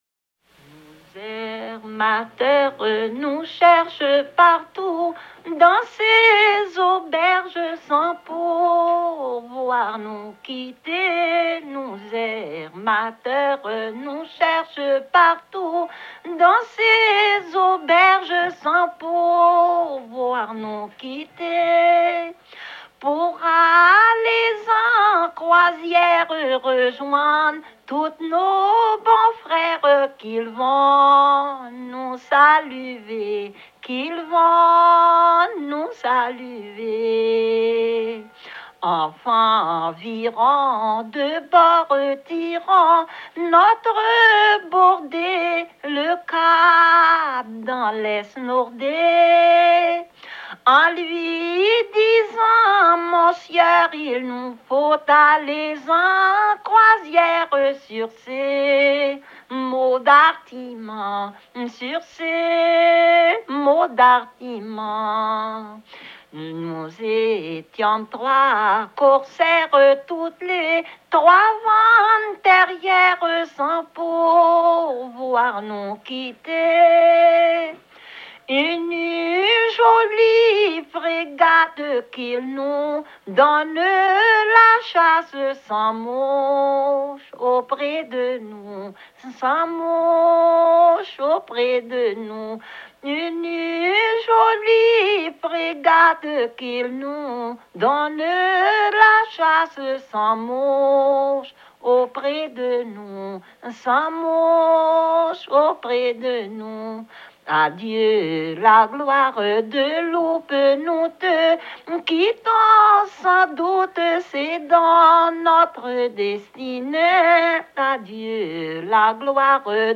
Localisation Louisiane (Plus d'informations sur Wikipedia)
Genre strophique
Catégorie Pièce musicale éditée